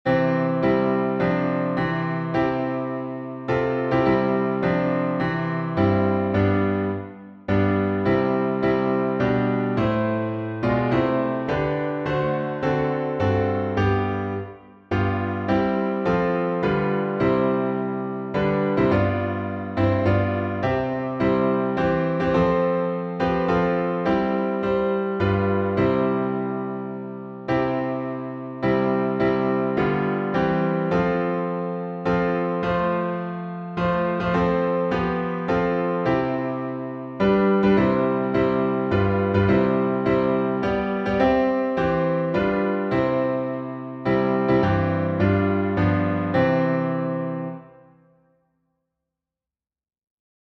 #4141: Christ Returneth — C major, alternate words | Mobile Hymns